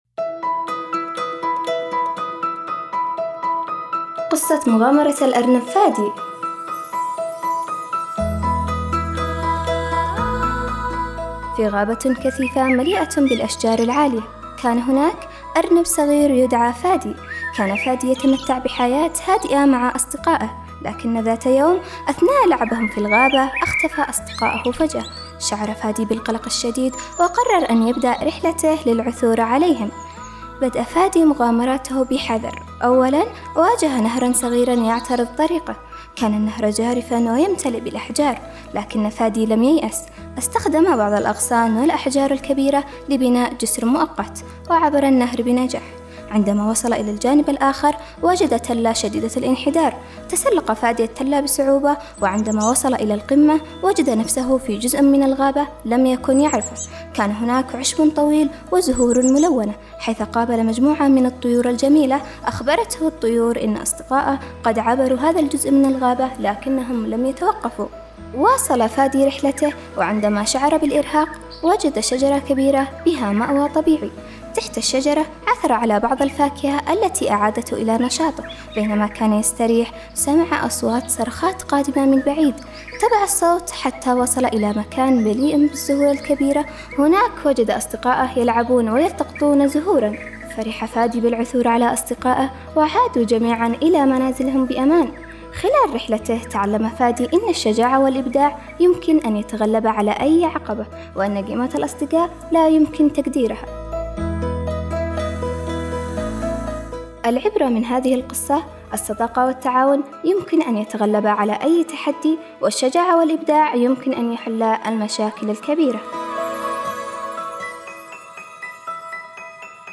قصة قصيرة